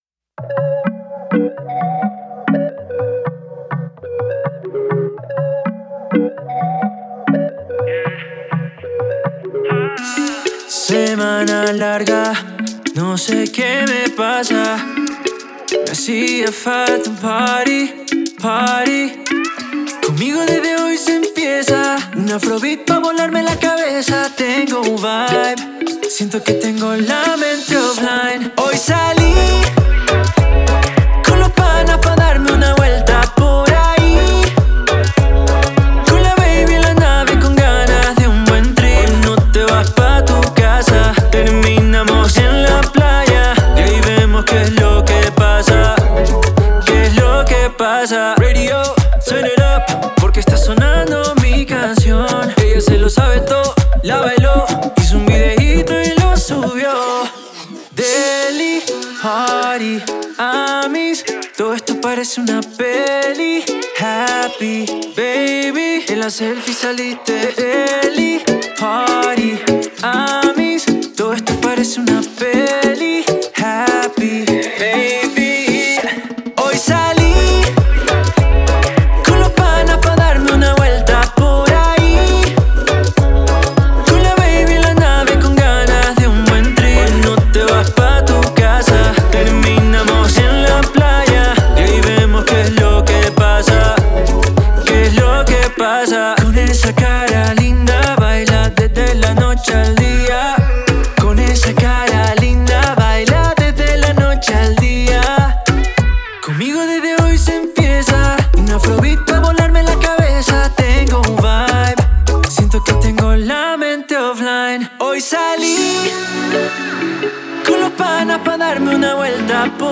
realidad  cargada de diversión y ritmo.
es la mezcla perfecta de un afrobeat y Pop